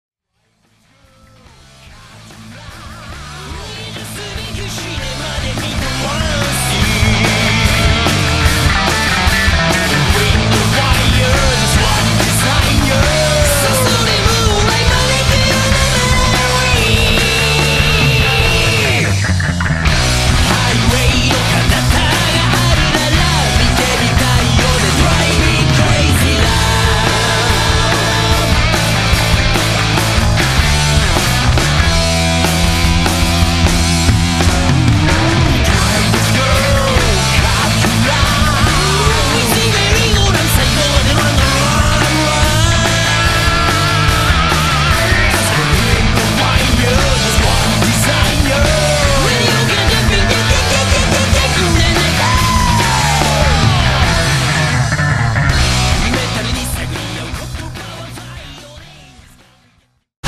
ハード・ロック・サウンドが身上だ。